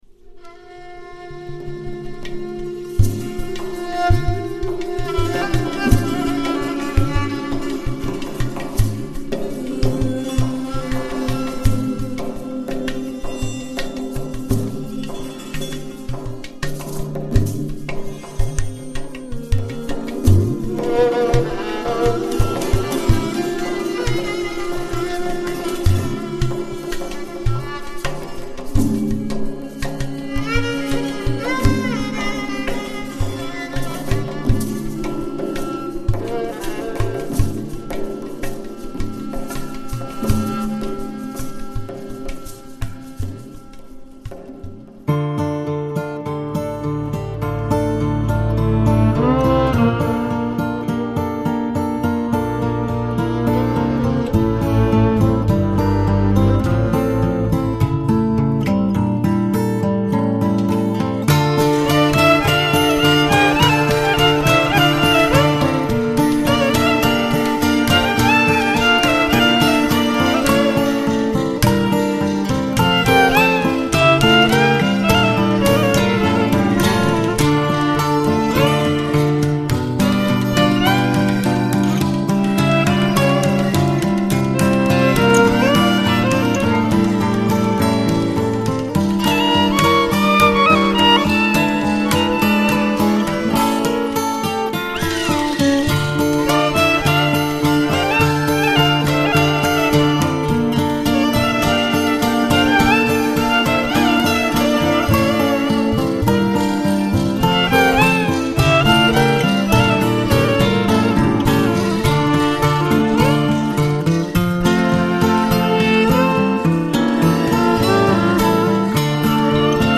Chosen for the beautiful composition using violin and Persian Kamancheh over guitar sample.